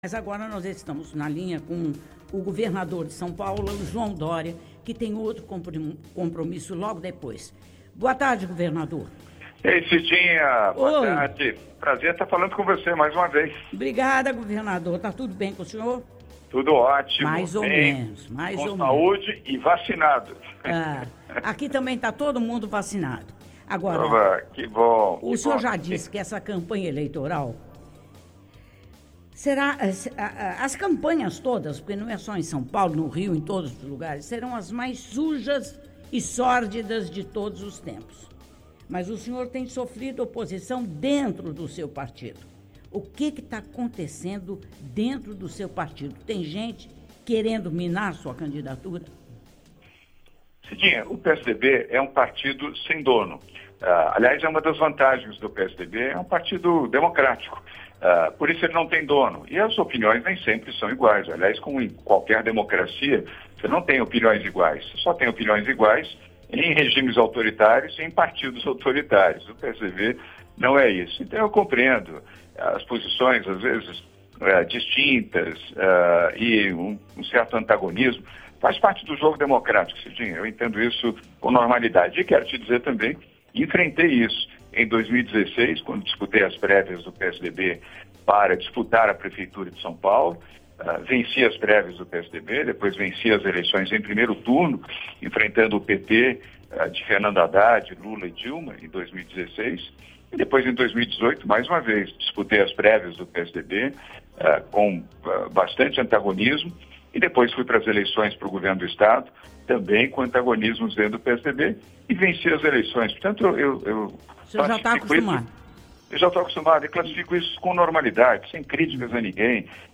João Doria, governador de São Paulo, participou do programa Cidinha Livre para falar sobre política, vacinação de crianças e sobre a corrida presidencial para as eleições de 2022.